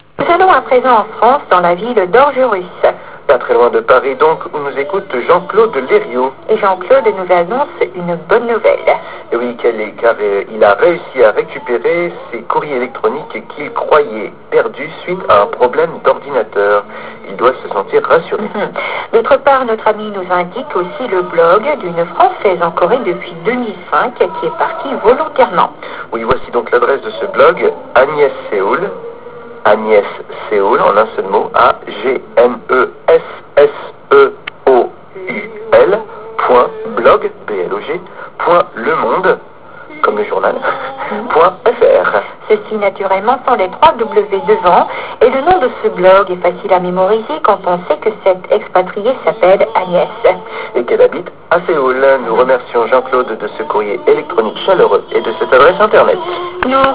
( toujours le brouillage de radio Roumanie)